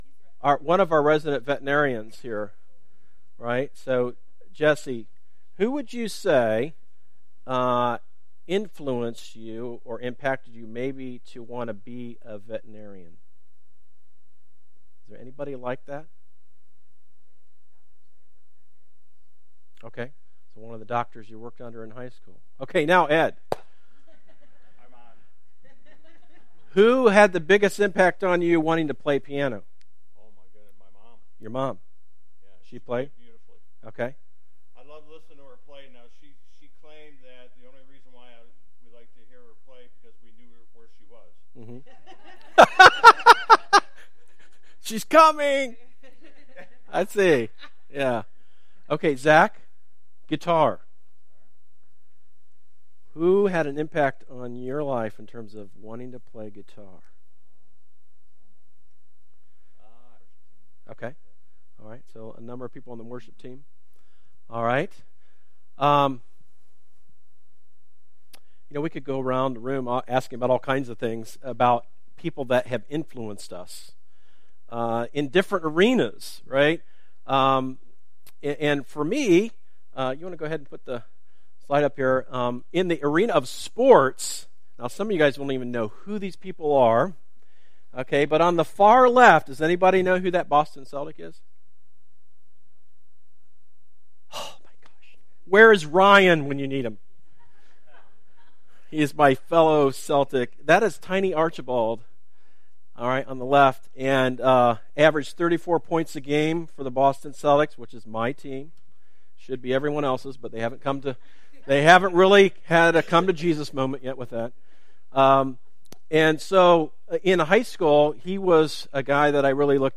Our audio sermon podcast is available on most podcasting services including Spotify, Apple Podcasts, Stitcher, Google Podcasts and more!